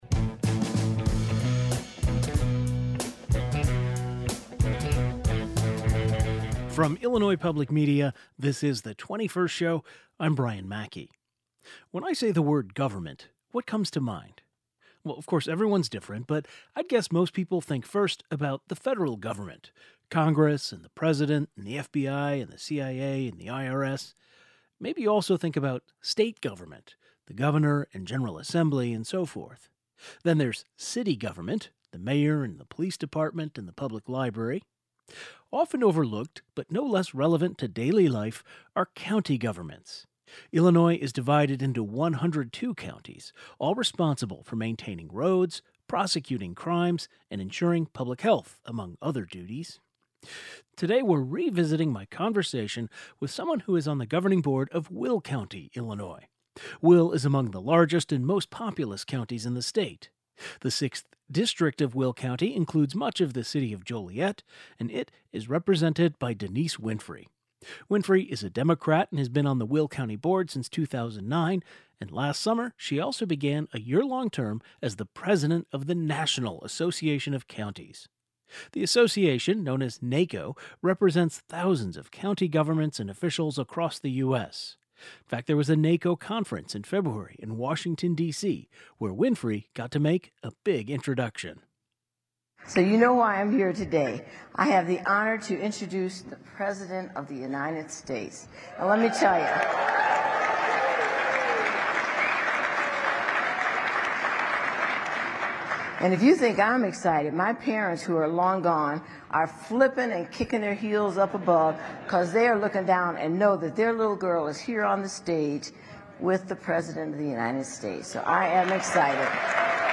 The 21st was joined by the president of the National Association of Counties, who also serves on the Will County Board.